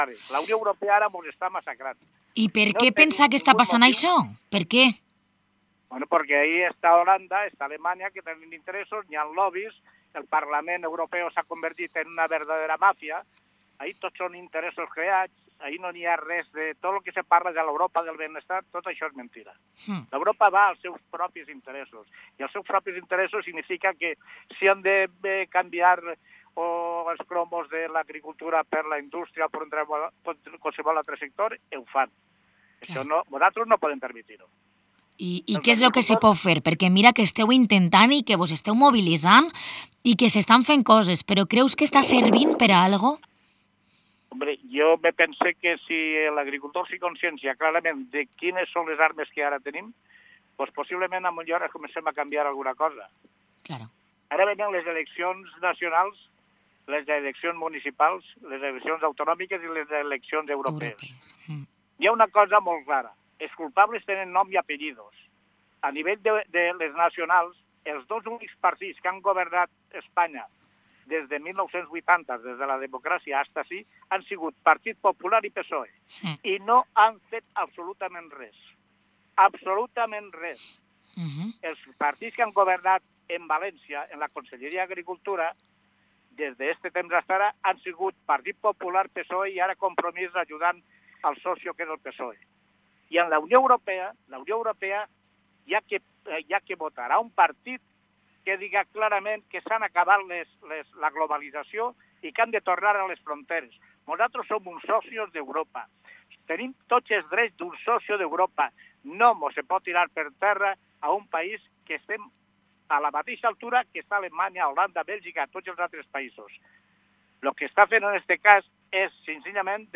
En una entrevista muy interesante
Cerramos Protagonistas de hoy con el alcalde de Vila-real, José Benlloch, que nos habla de la actual situación de la adquisición del Hostal del Rey.